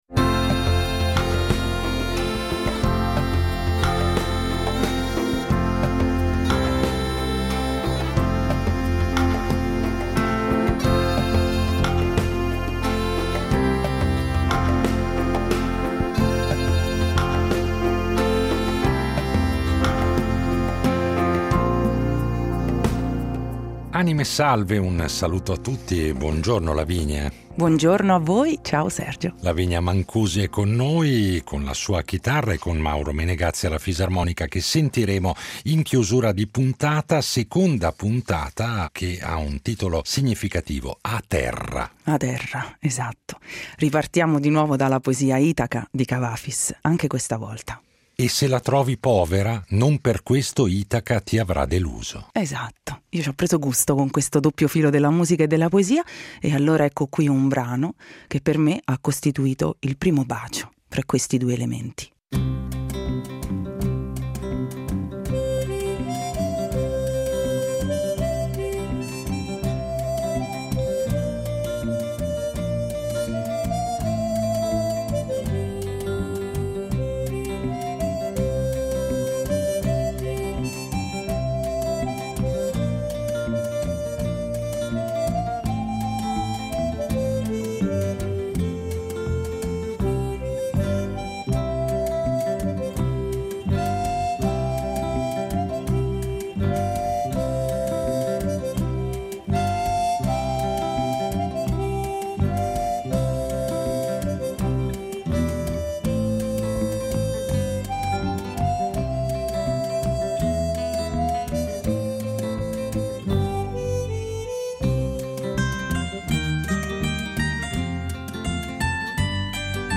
La nostra ospite è una giovane cantante e musicista romana che si è innamorata da piccolissima della musica popolare, complice anche un viaggio nel Salento durante il quale ha avuto modo di ammirare dal vivo Pino Zimba e i suoi tamburelli.
Come sempre accade ad Anime Salve